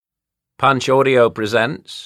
Audio knihaOedipus: The King (EN)
Ukázka z knihy